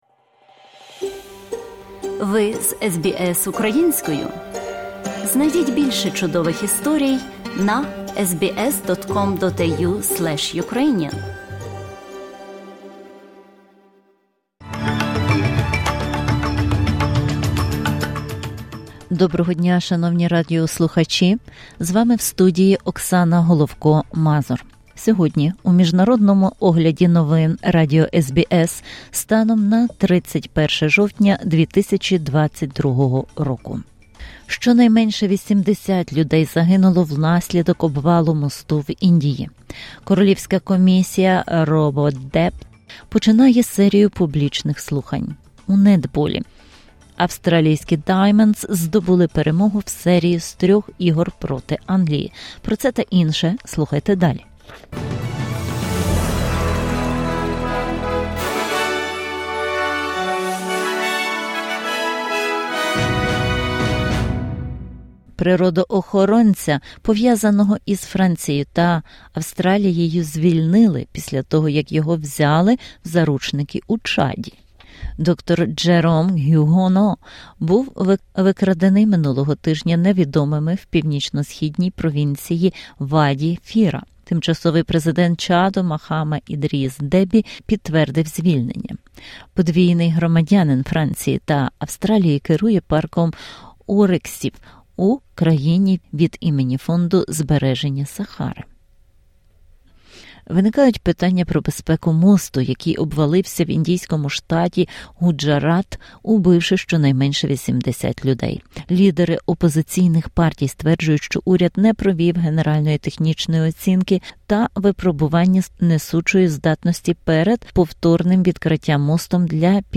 SBS news in Ukrainian 31/10/2022